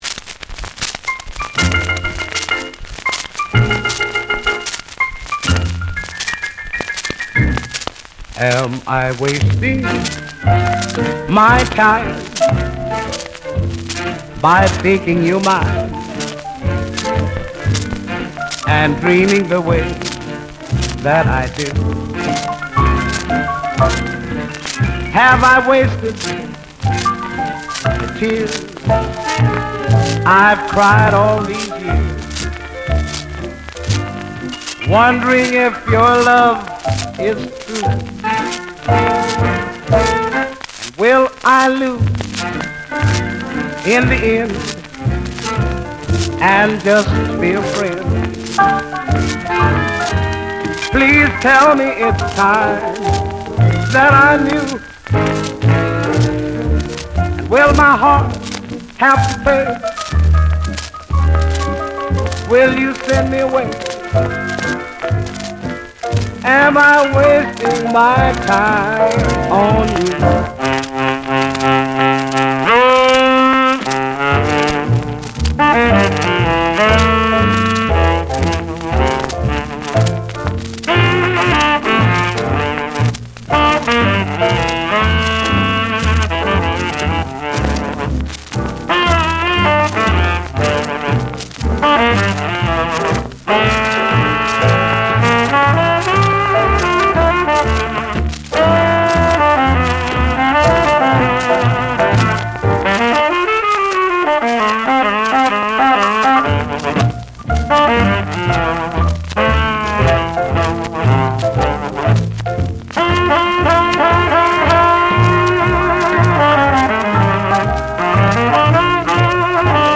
All of them are single sided with blank backs (other than what appears to be some test cutting on the back of one record) They seem to be demos from unknown artists.. Probably recorded on these discs as they were performed… possibly these are copies of other records.